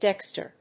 音節dex・ter 発音記号・読み方
/dékstɚ(米国英語), dékstə(英国英語)/